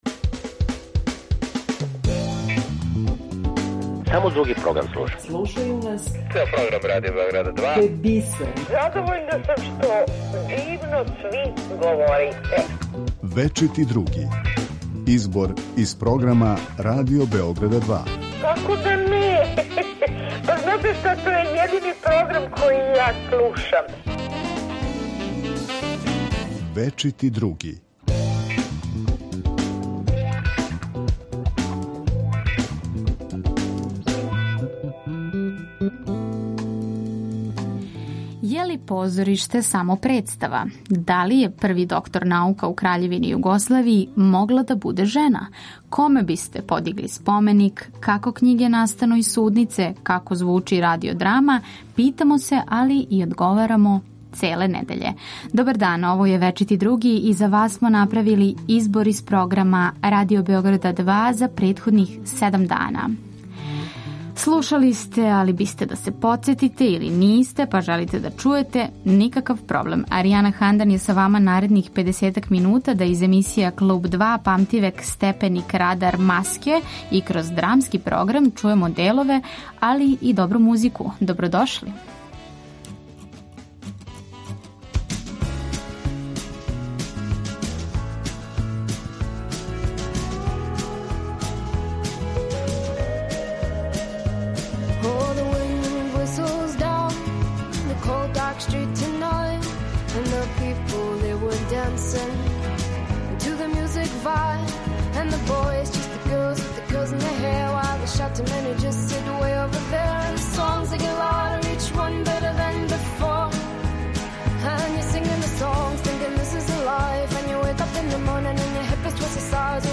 „Фрида Кало" наслов је радио драме из које део издвајамо за вас
Осим тога, чућете и друге госте Радио Београда 2, као и најаве предстојећих догађаја.